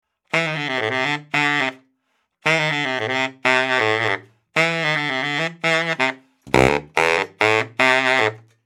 有趣的中音萨克斯03
描述：牛逼的放克男中音萨克斯循环，供你作为你创作的一部分使用。
标签： 110 bpm Funk Loops Woodwind Loops 755.42 KB wav Key : Unknown
声道单声道